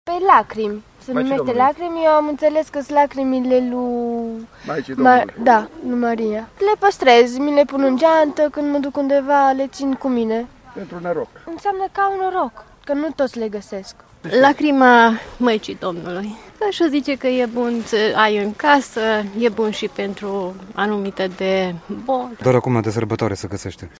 06-voxuri-Radna-lacrima.mp3